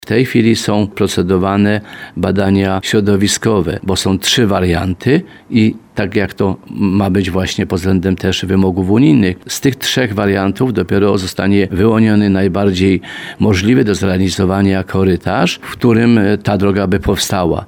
– W tej chwili są procedowane badania środowiskowe i tak jak ma być to pod względem wymogów unijnych. Z trzech wariantów, które już są gotowe, zostanie dopiero wyłoniony najbardziej możliwy do zrealizowania korytarz, w którym ta droga by powstała – mówi starosta limanowski, Mieczysław Uryga.